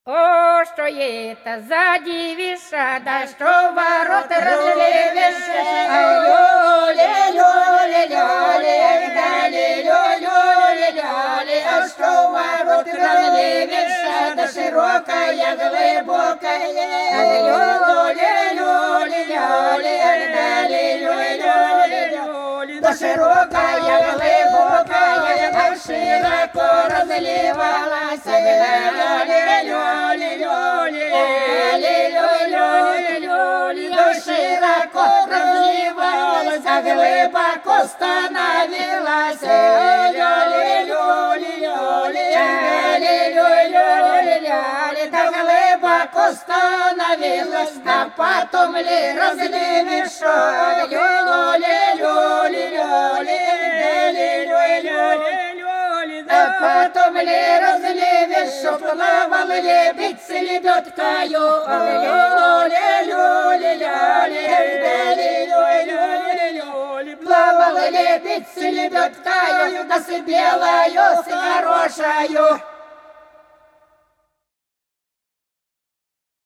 По-над садом, садом дорожка лежала Ой, что это за девица - масленичная (с.Плёхово, Курская область)